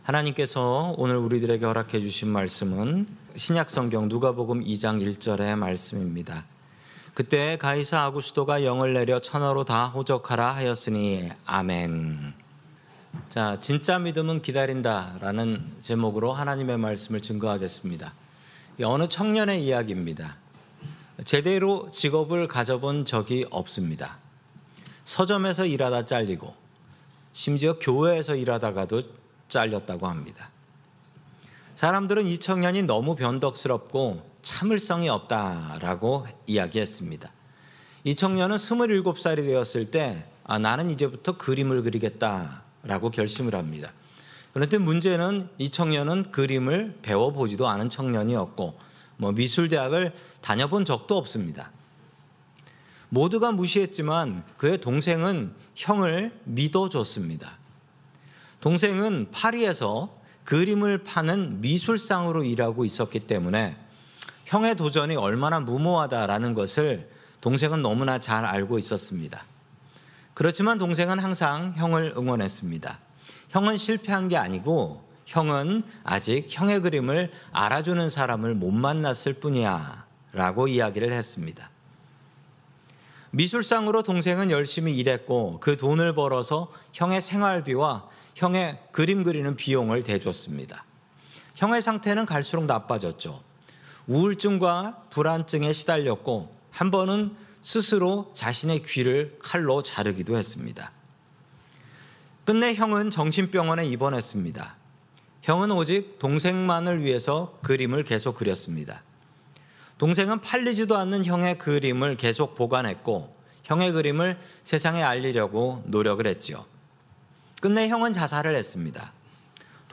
샌프란시스코 은혜장로교회 설교방송